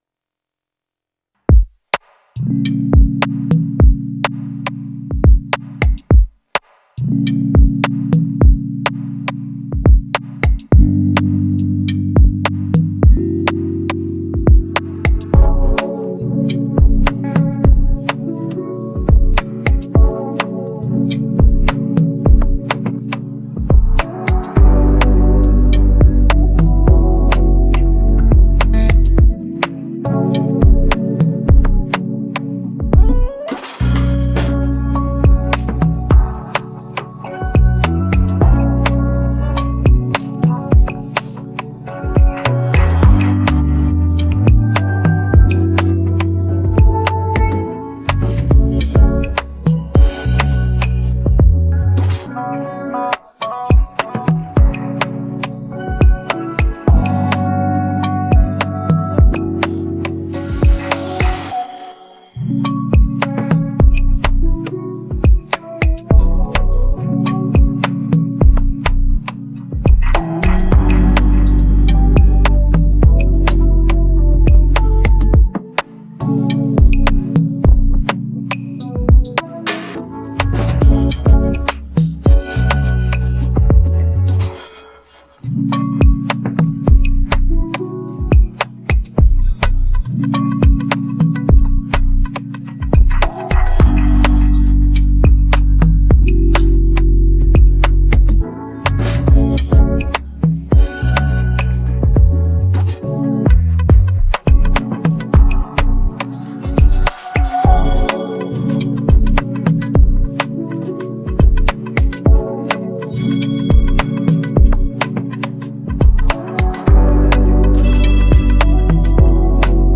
Hip hophiphop trap beats